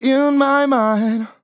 Categories: Vocals Tags: dry, english, In, LOFI VIBES, LYRICS, male, mind, My, sample
MAN-LYRICS-FILLS-120bpm-Am-19.wav